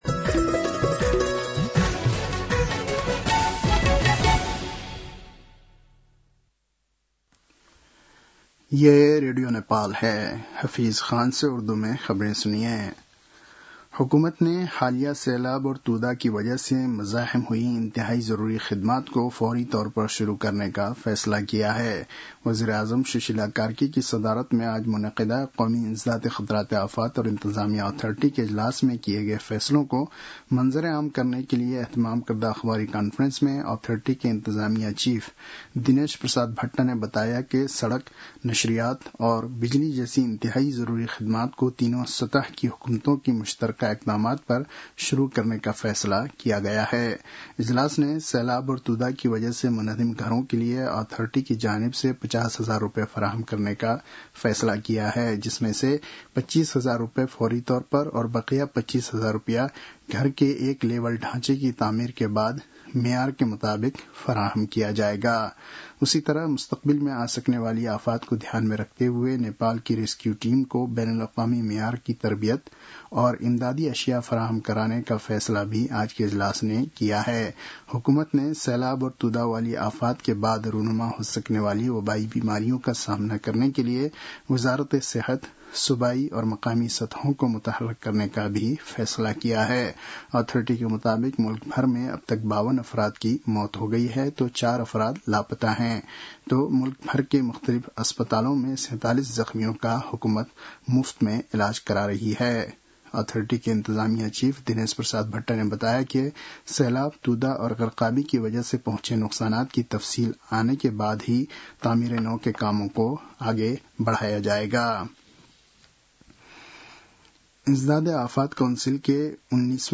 उर्दु भाषामा समाचार : २१ असोज , २०८२